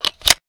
weapon_foley_pickup_11.wav